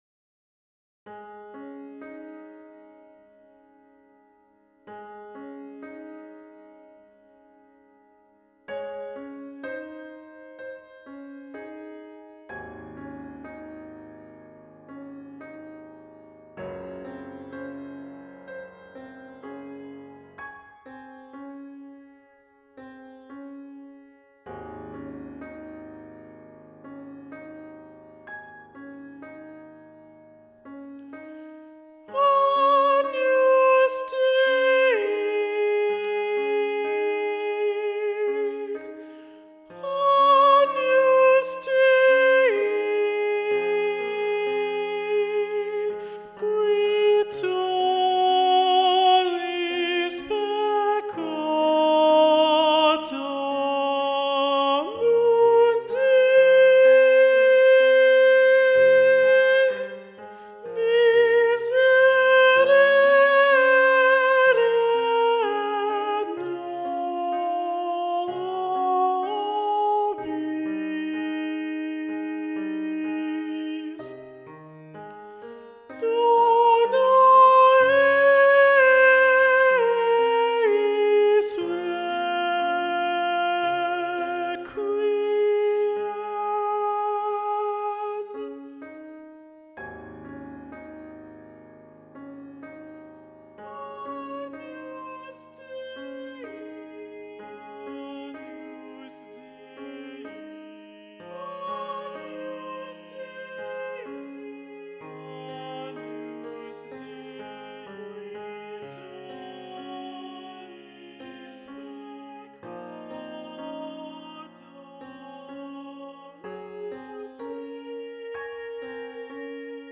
Video Only: Agnus Dei - Boy Solo Predominant